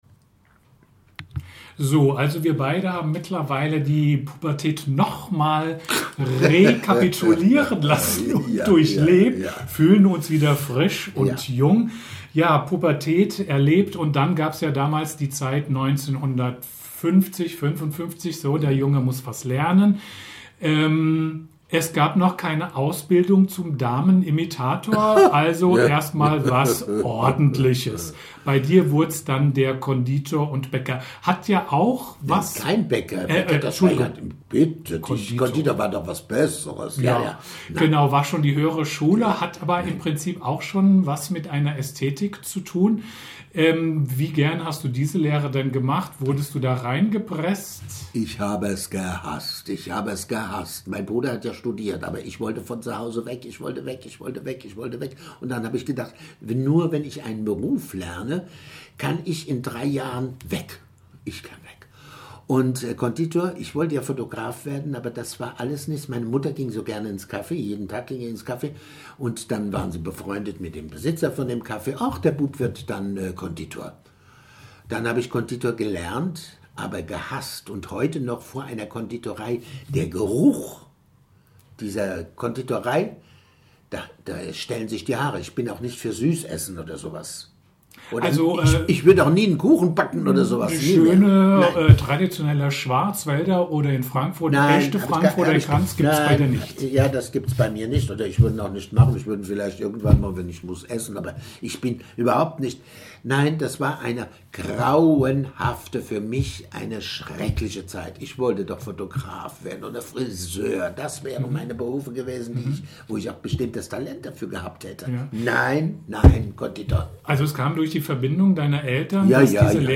Teil 2 des Interviews